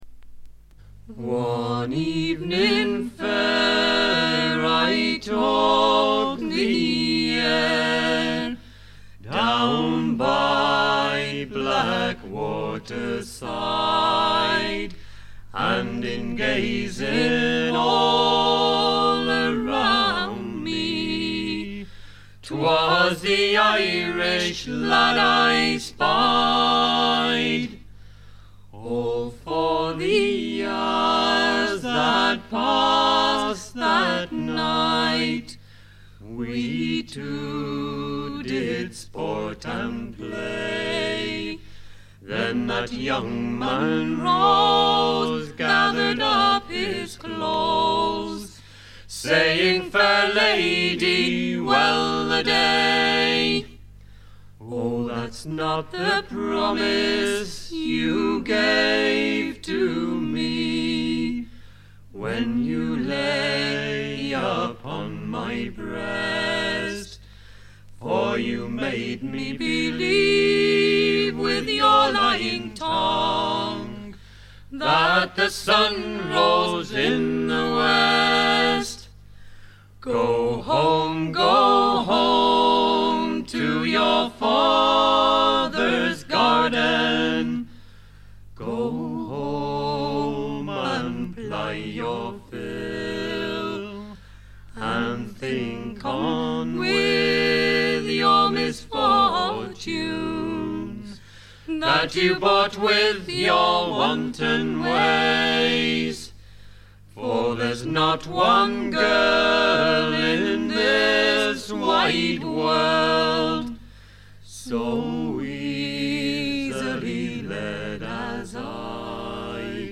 フィメールを含む4人組。
試聴曲は現品からの取り込み音源です。
Recorded at Mid Wales Sound Studio June 1976